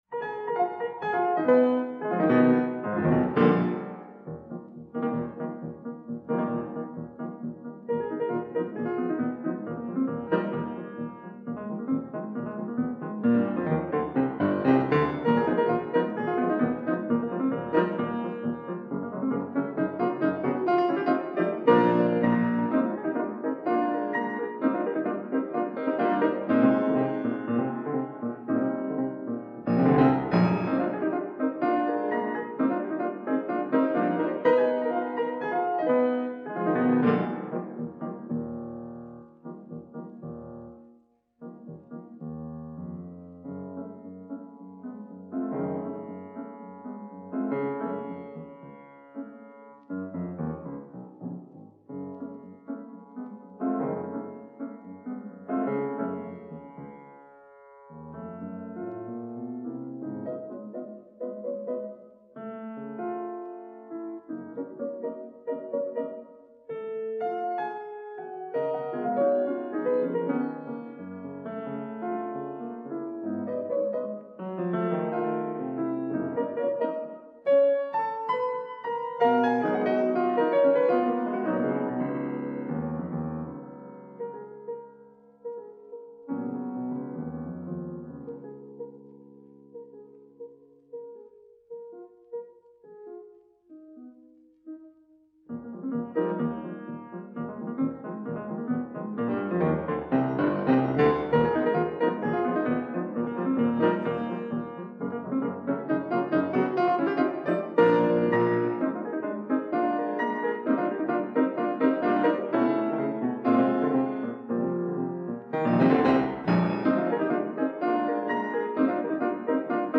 фортепианный цикл.